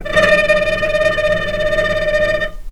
healing-soundscapes/Sound Banks/HSS_OP_Pack/Strings/cello/tremolo/vc_trm-D#5-pp.aif at b3491bb4d8ce6d21e289ff40adc3c6f654cc89a0
vc_trm-D#5-pp.aif